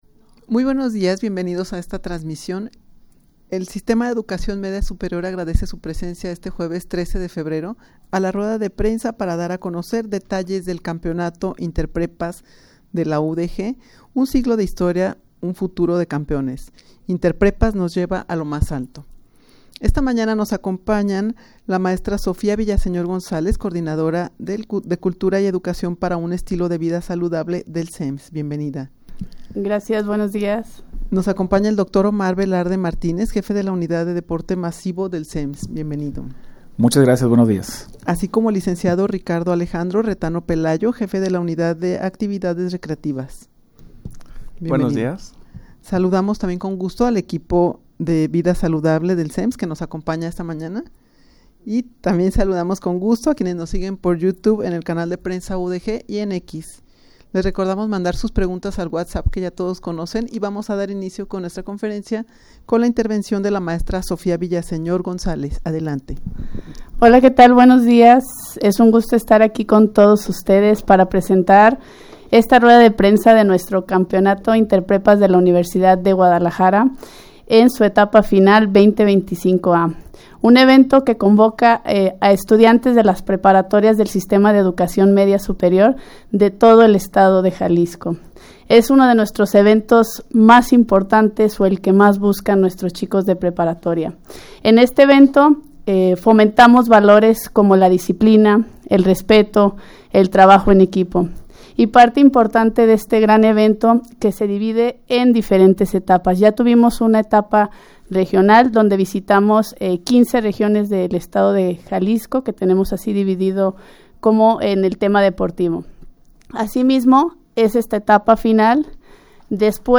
rueda-de-prensa-para-dar-a-conocer-detalles-del-campeonato-interprepas-de-la-udeg.mp3